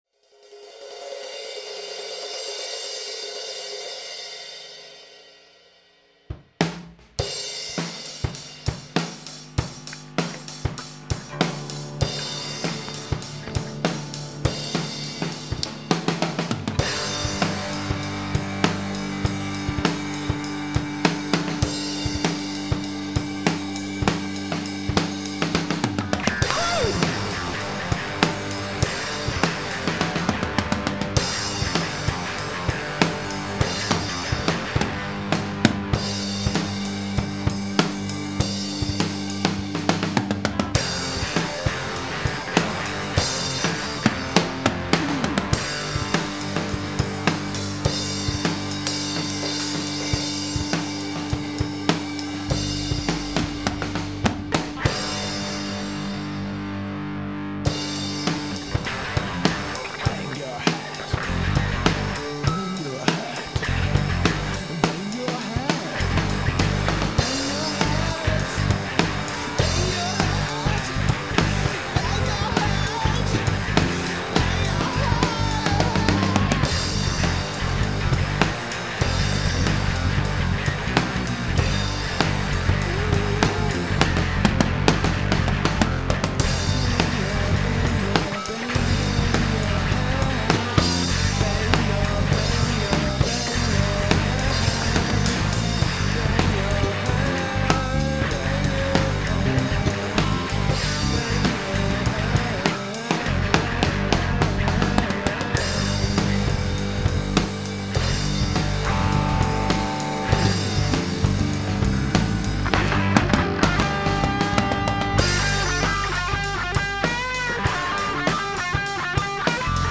Es ging mir vor allem darum, meiner Kreativität freien Lauf zu lassen und gleichzeitig mein kleines Heimstudio auszuprobieren.
• Alles wurde an einem Tag geschrieben, gespielt, aufgenommen und produziert.
Hier hab ich mal ein verrücktes Experiment gewagt: Ich hab zuerst die Schlagzeugspur eingespielt und dann den Gitarrenriff spontan dazugespielt. Das gilt auch für den „Gesang“ und damit ist es das spontanste auf diesem Album.